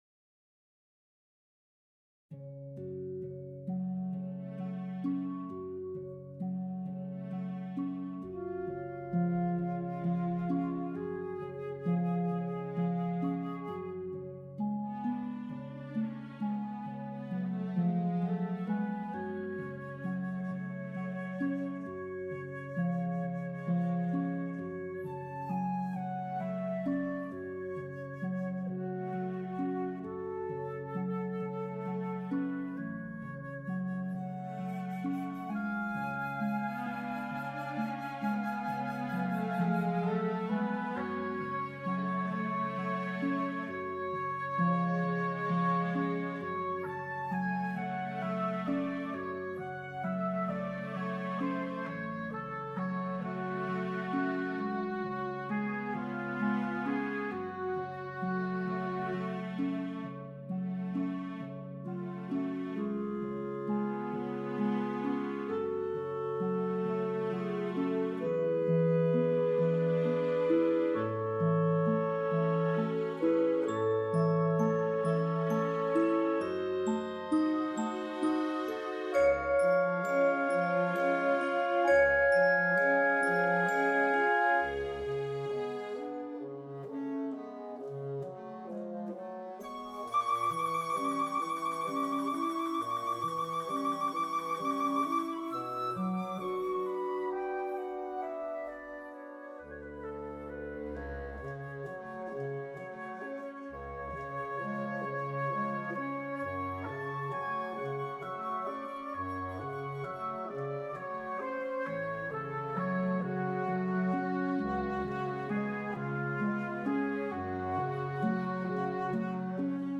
Mendelssohn orchestration (Barcarolle Op.30 No.6)
Current dynamics are intended for midi playback: so I...